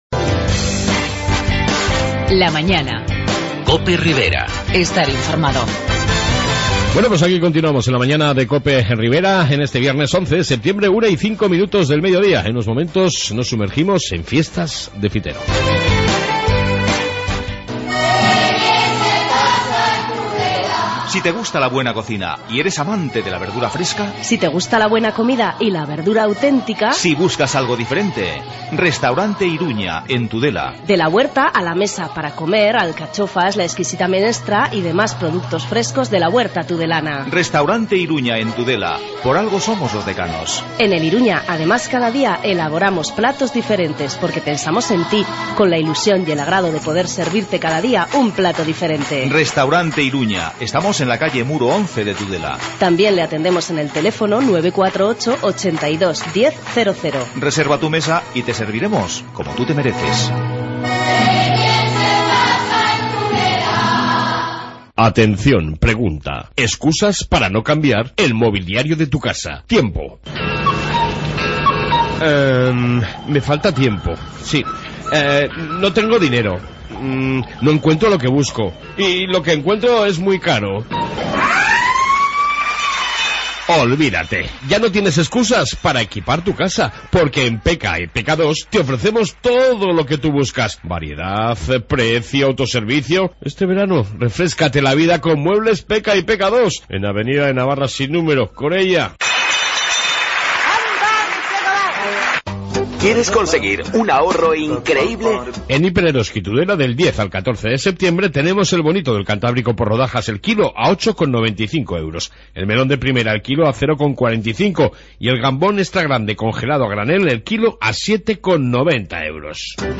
AUDIO: Entrevista Alcalde de Fitero y Tiempo pàra el Motor con Peugeot Marco